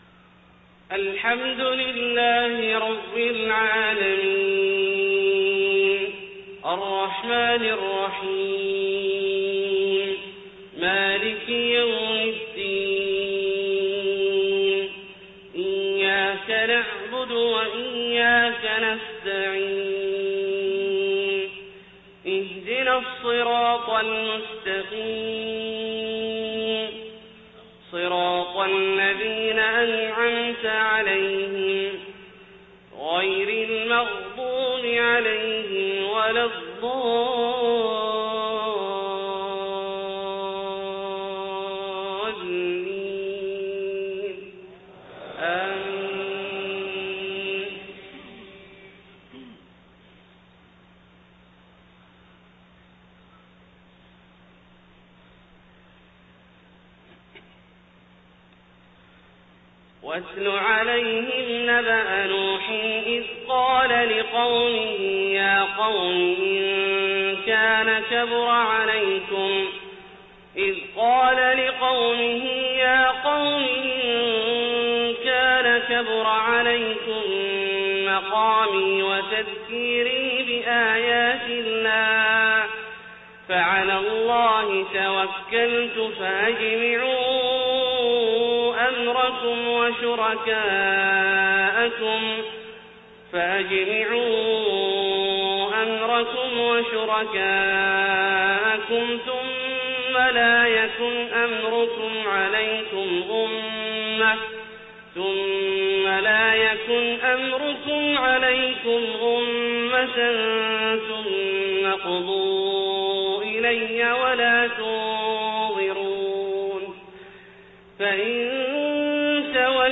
صلاة الفجر 1 ربيع الأول 1431هـ من سورة يونس71-92 > 1431 🕋 > الفروض - تلاوات الحرمين